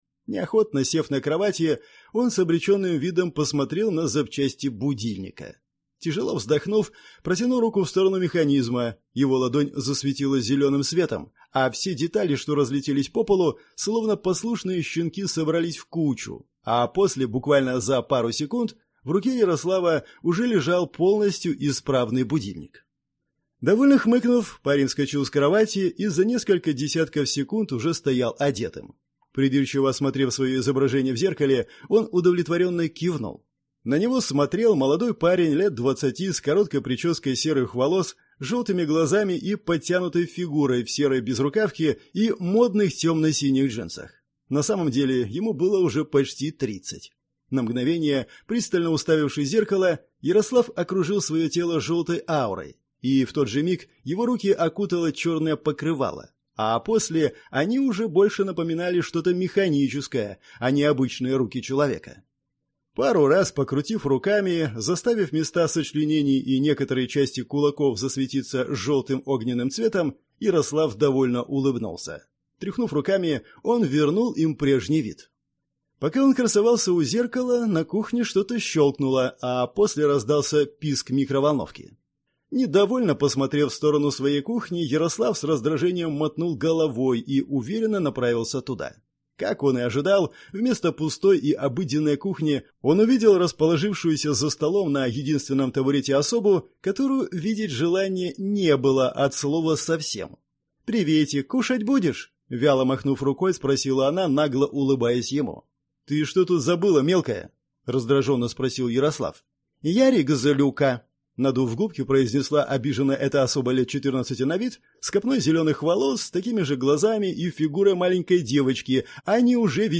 Аудиокнига Система. Девятый уровень. Книга 2 | Библиотека аудиокниг